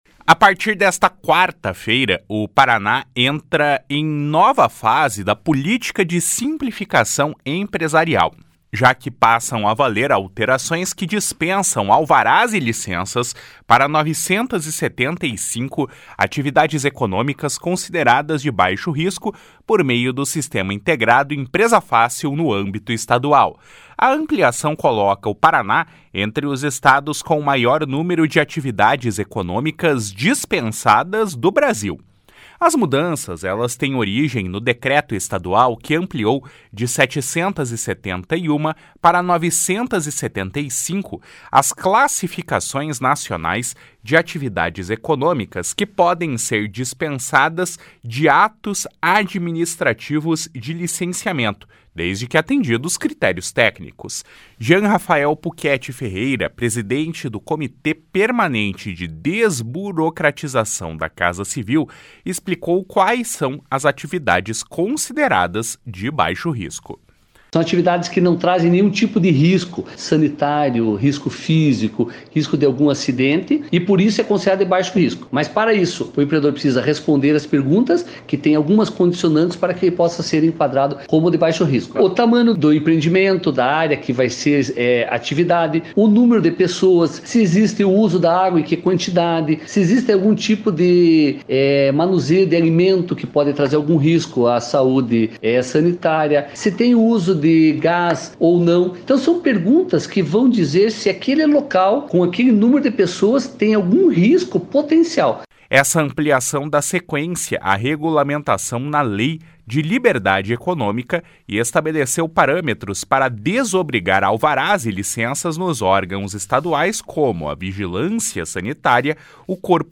Jean Rafael Puchetti Ferreira, presidente do Comitê Permanente de Desburocratização da Casa Civil, explicou quais são as atividades consideradas de baixo risco. // SONORA JEAN PUCHETTI //